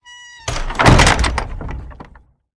doorclose1.ogg